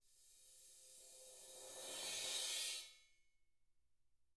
Index of /90_sSampleCDs/ILIO - Double Platinum Drums 1/CD4/Partition I/RIDE SWELLD